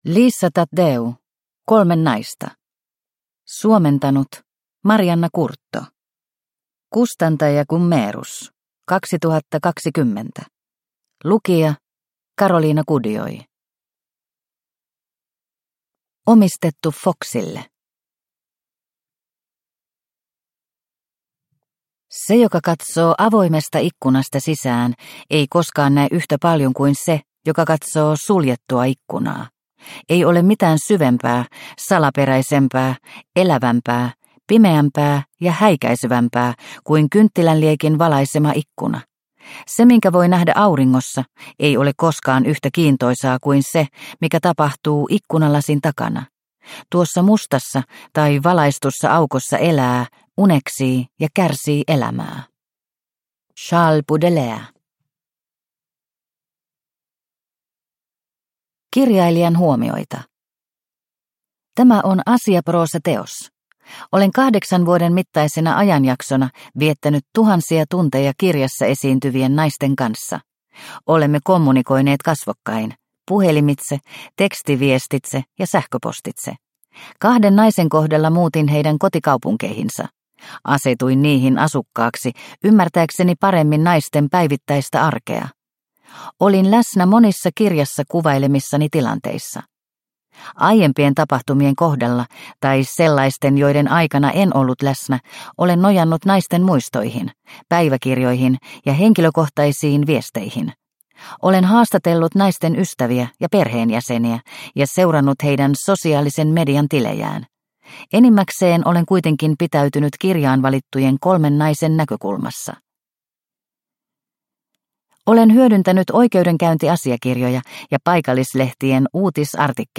Kolme naista – Ljudbok – Laddas ner